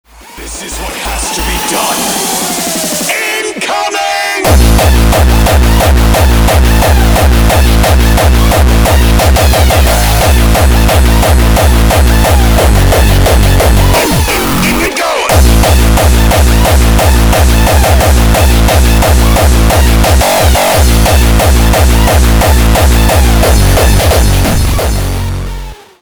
• Качество: 320, Stereo
Хард Басс